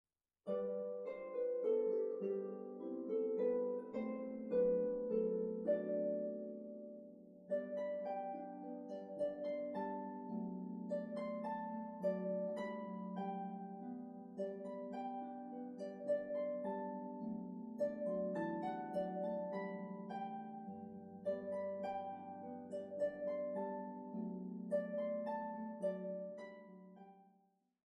solo harp demo files